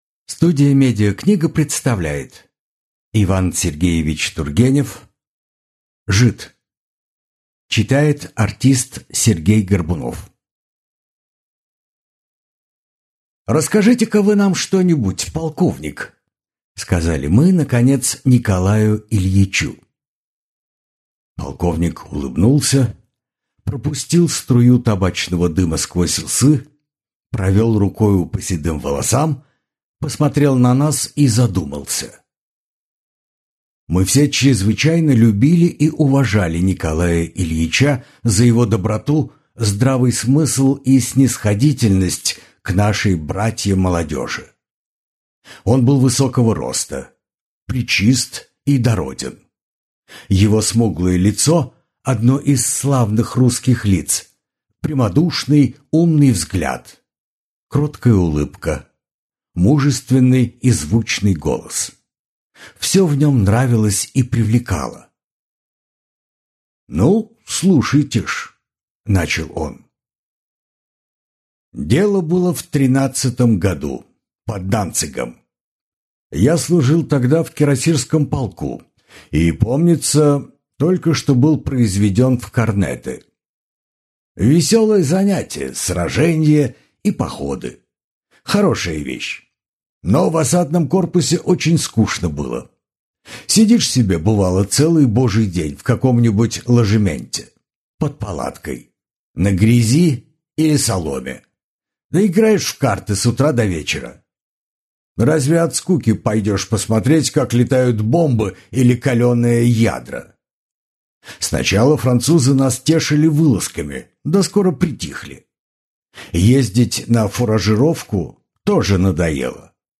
Аудиокнига Жид | Библиотека аудиокниг